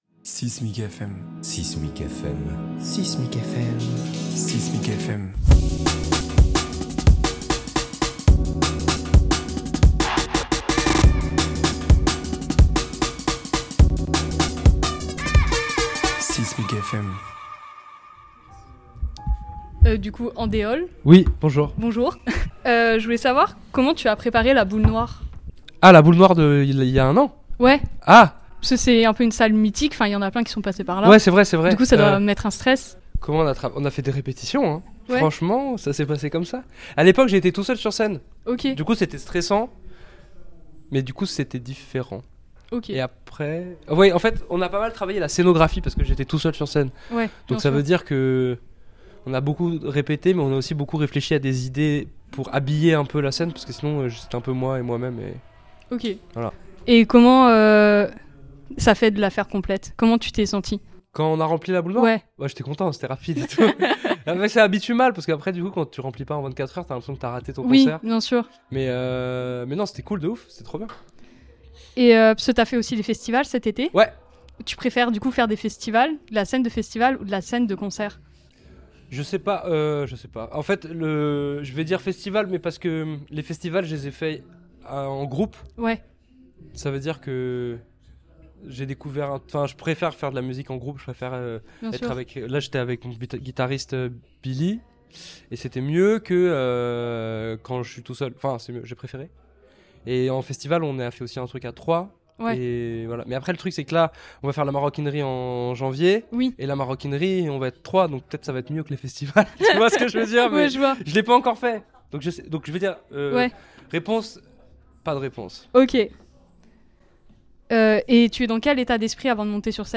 6mic FM est une nouvelle collaboration entre 6mic et RadioZai où les musiciens discutent avec nous dans ce lieu emblématique d'Aix-en-Provence.